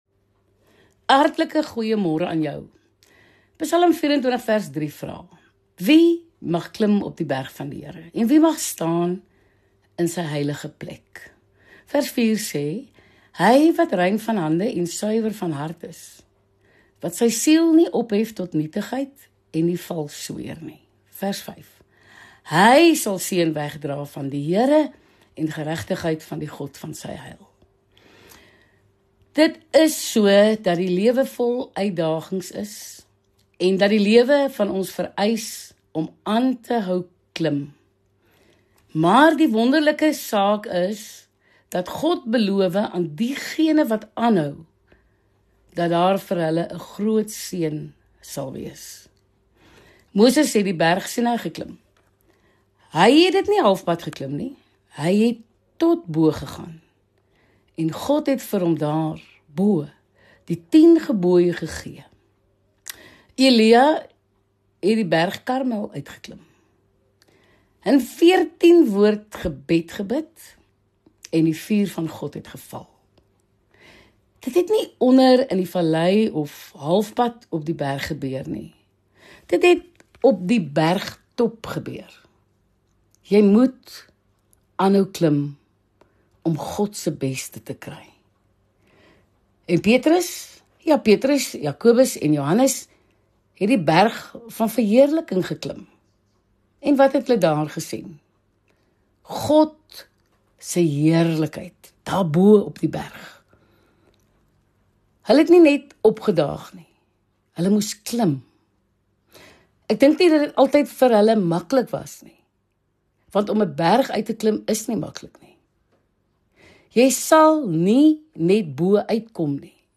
Motiveringsspreker, teoloog en verhoudingsspesialis